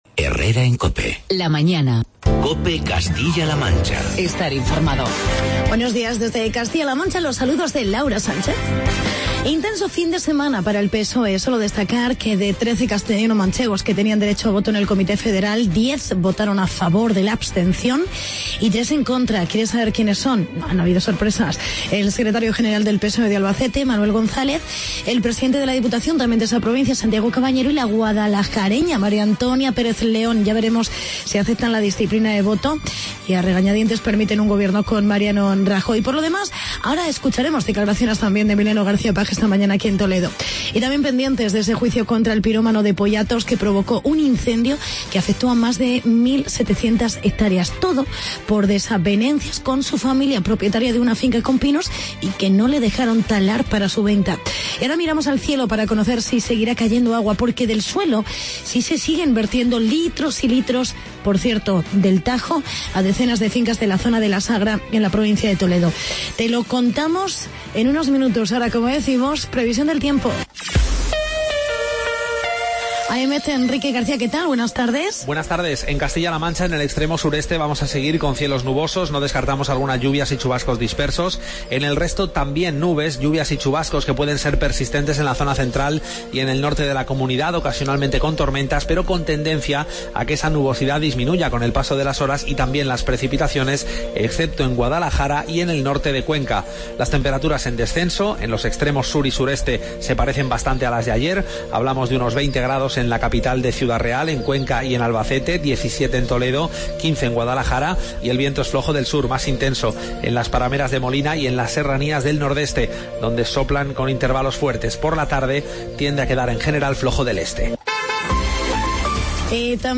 Actualidad y entrevista con el alcalde de Olías del Rey sobre inundaciones en la Sagra por rotura de tuberías regadío del Tajo.